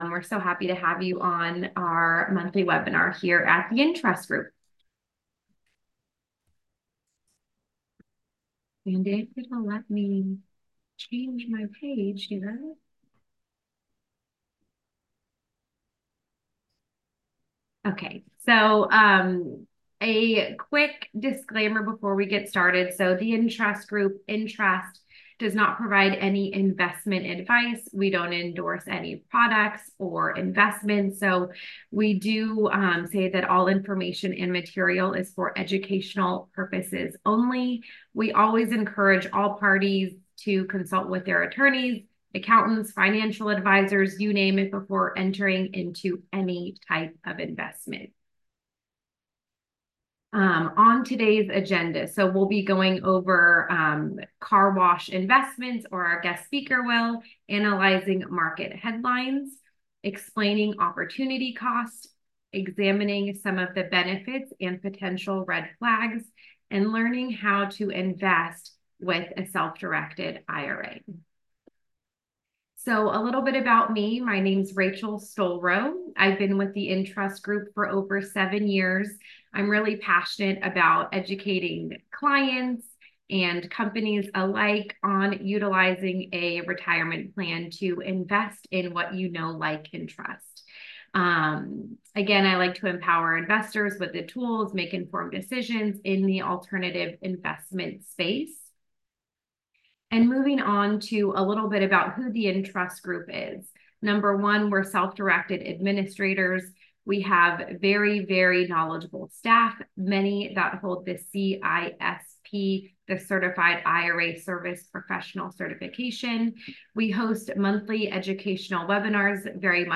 In this webinar replay, you will see what experts across a variety of alternatives think about the current market forces, how to find & vet investments and how to stay safe in a world full of scammers attacking your defenses.